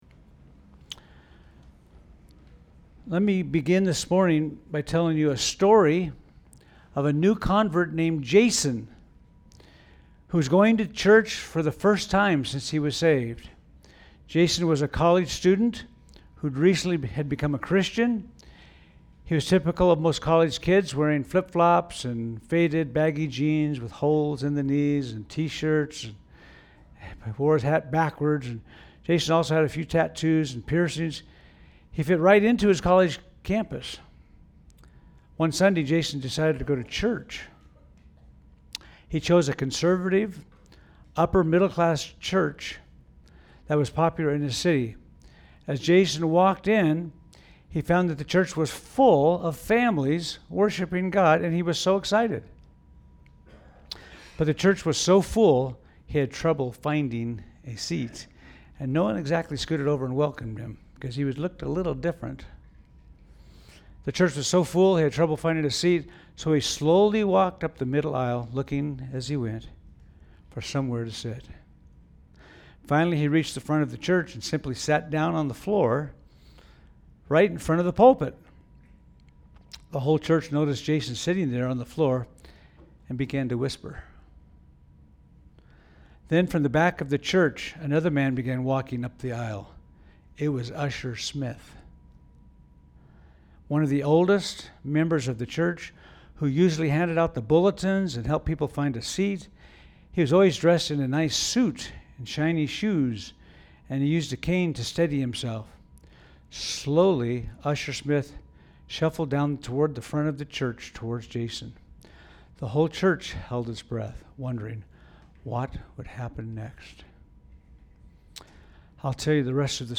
A message from the series "Matthew."